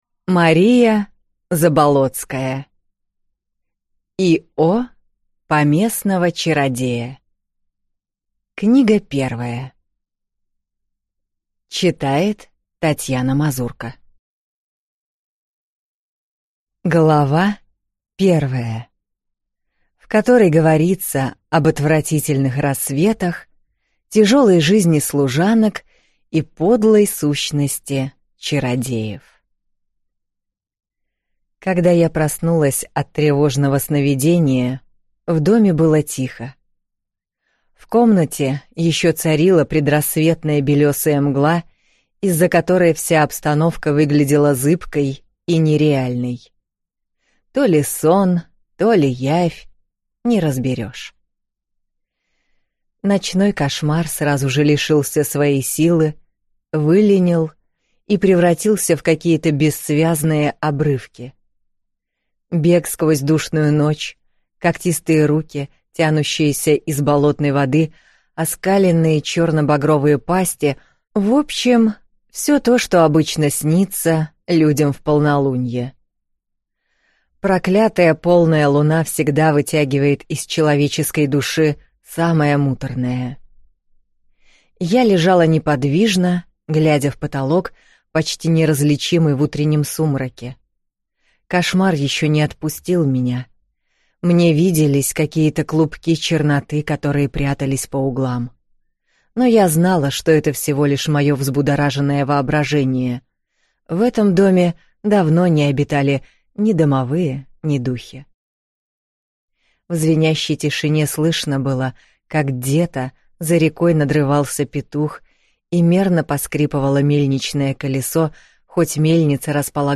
Аудиокнига И.о. поместного чародея. Книга 1 | Библиотека аудиокниг